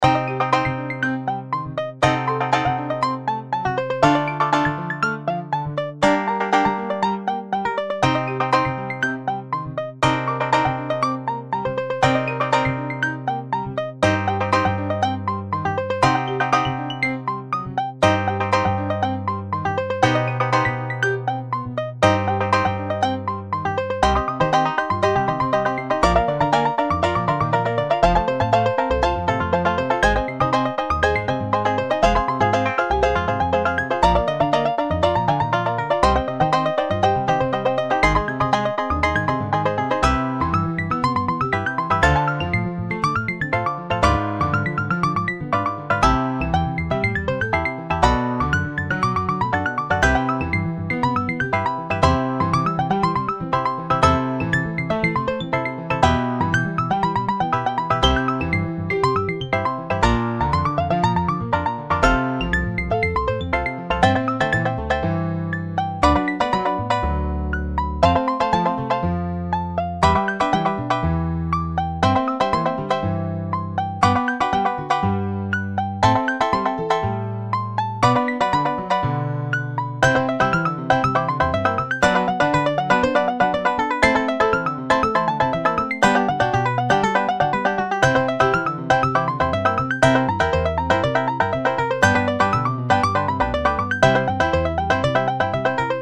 バンジョー、ピアノ、アコースティックベース
BGM